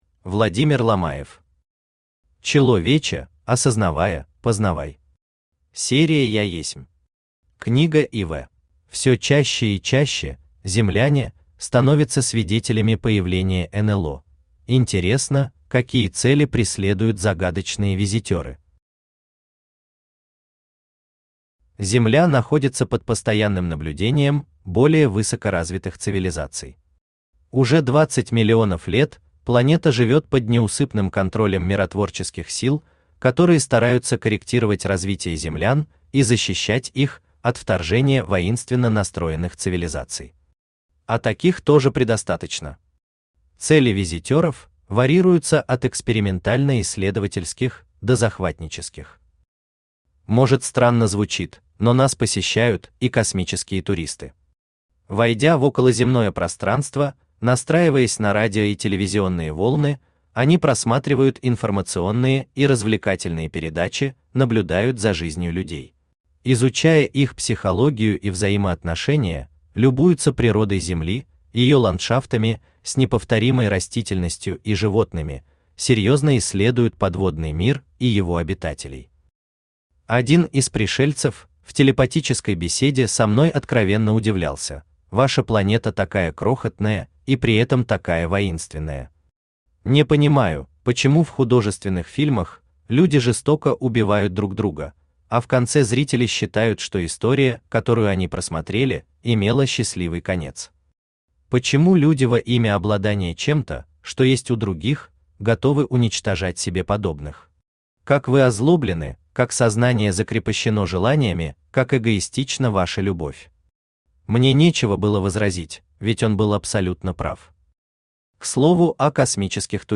Аудиокнига ЧЕЛоВЕЧЕ, осознавая, познавай.
Книга IV Автор Владимир Федорович Ломаев Читает аудиокнигу Авточтец ЛитРес.